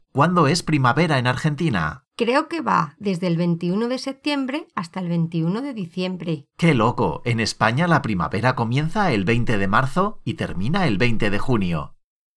Recording: 0034 Level: Beginners Spanish Variety: Spanish from Spain
Transcribe the whole conversation by writing word by word what the speakers say.